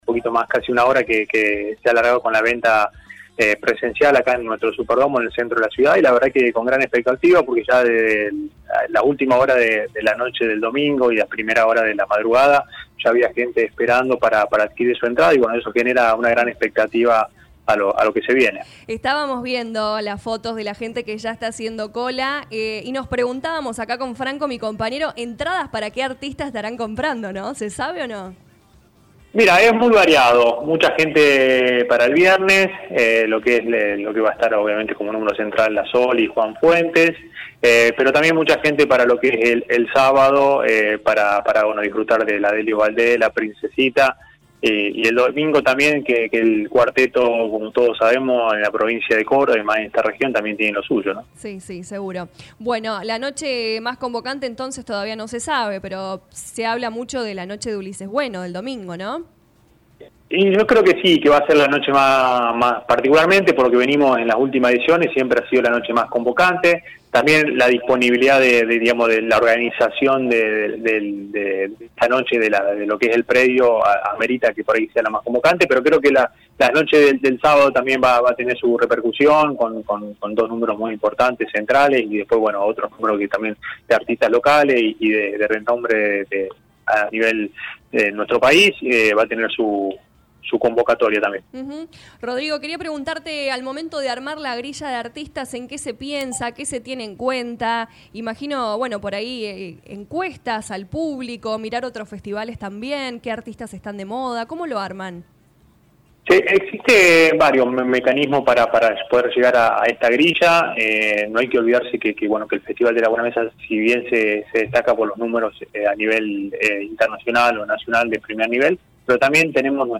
Lo confirmó en diálogo con LA RADIO 102.9 FM el director de Gobierno Rodrigo Franceschi al anunciar que desde las 9 horas del lunes largó la venta de entradas en el Superdomo de San Francisco y desde las 10 en Eden Entradas de manera online.